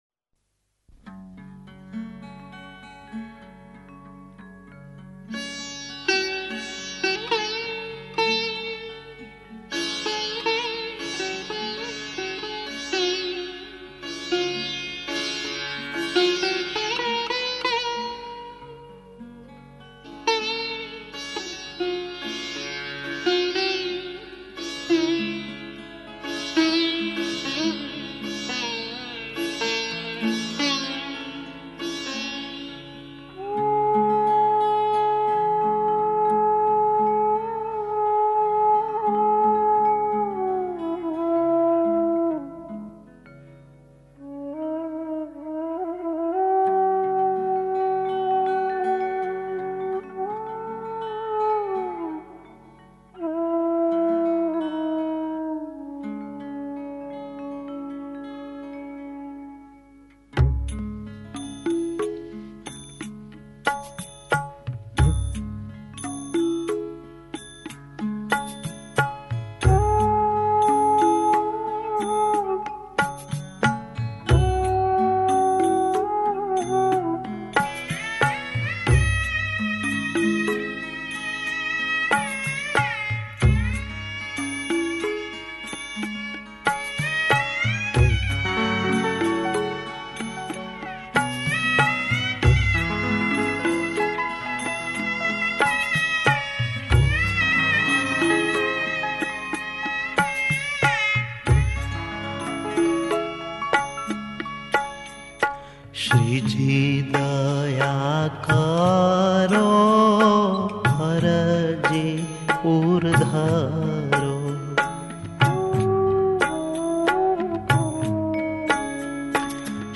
કાફી
રેકોર્ડીંગ ગુણવત્તા-સામાન્ય પરંપરાગત શ્રીજી દયા કરો Studio Audio